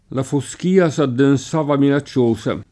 la foSk&a S addenS#va mina©©1Sa] (Pirandello)